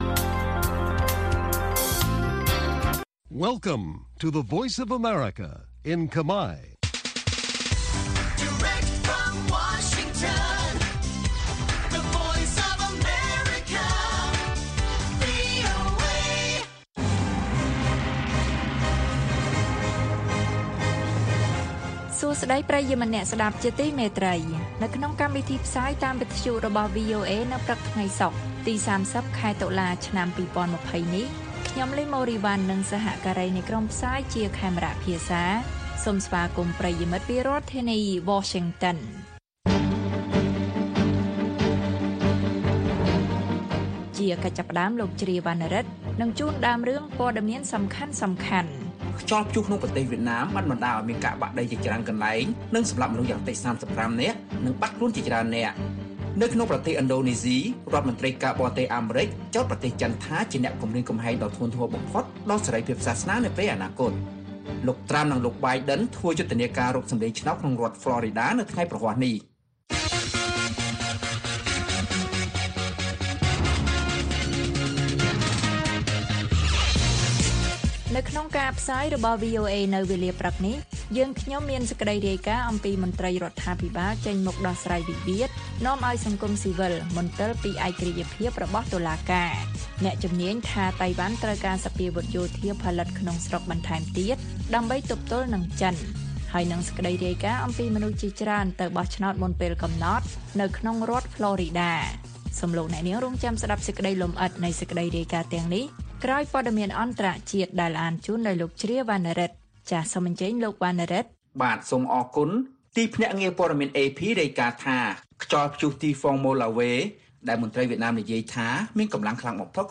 ព័ត៌មានពេលព្រឹក៖ ៣០ តុលា ២០២០